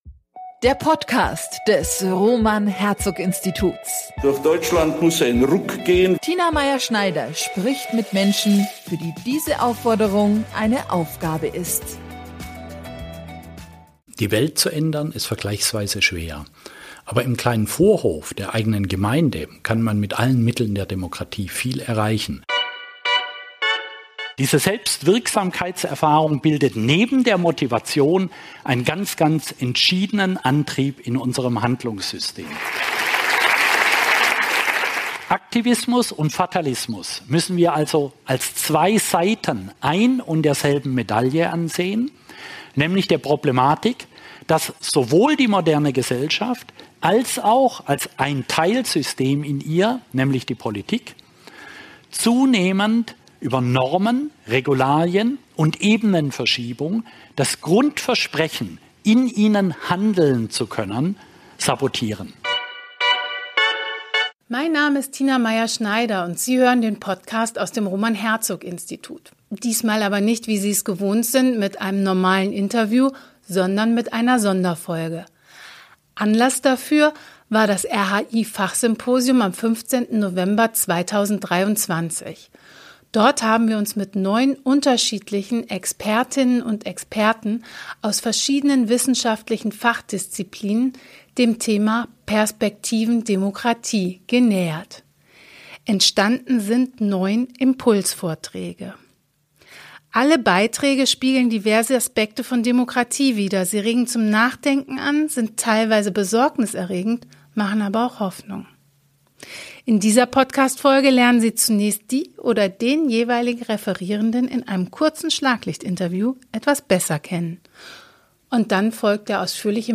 Im Rahmen des RHI-Symposiums am 15. November 2023 haben wir uns gemeinsam mit Expertinnen und Experten unterschiedlicher wissenschaftlicher Disziplinen dem Thema „Perspektiven Demokratie“ gewidmet. Ihre Beiträge spiegeln diverse Aspekte von Demokratie wider, sie regen zum Nachdenken an, sind teils durchaus besorgniserregend und machen teils Hoffnung.
Dann folgt der ausführliche Mitschnitt des Vortrags.